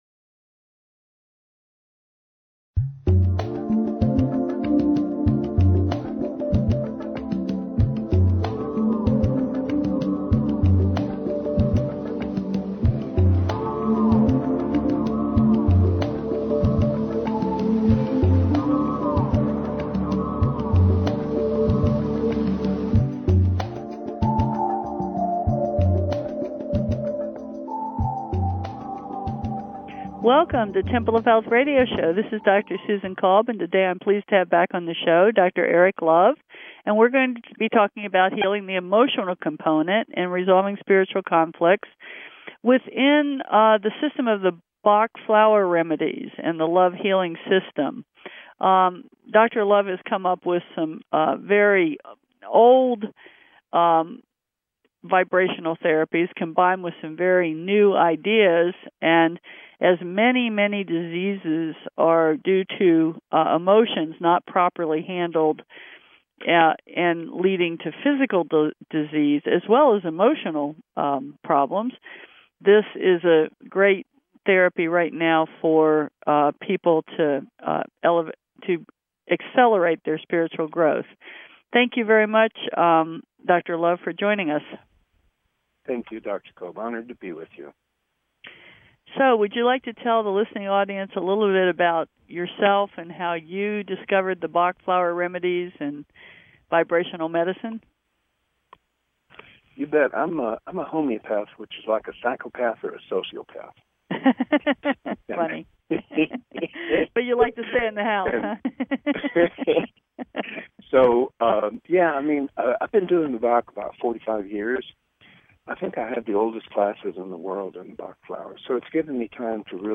Talk Show Episode, Audio Podcast, Temple of Health Radio Show and Guest